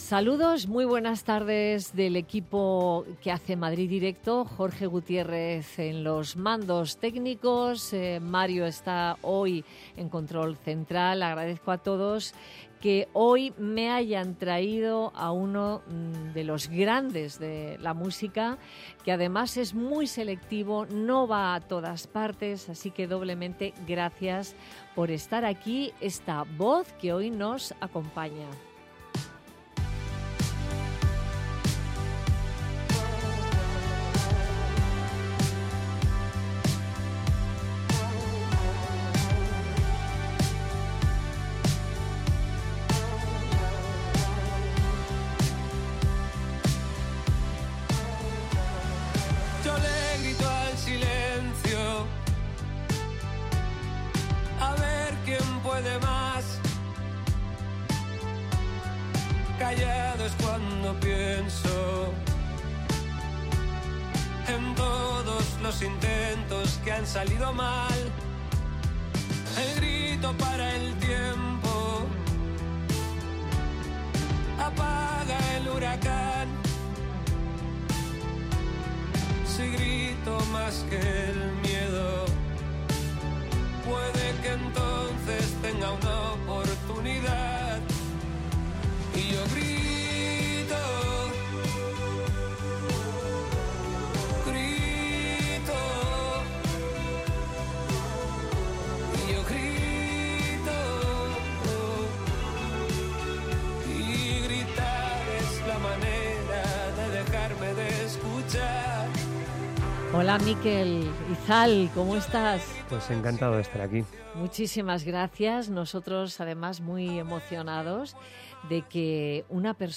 El cantante Mikel Izal ha pasado por los micrófonos de Madrid directo con Nieves Herrero para hablar de su próximo disco El miedo y el paraíso que verá la luz en noviembre.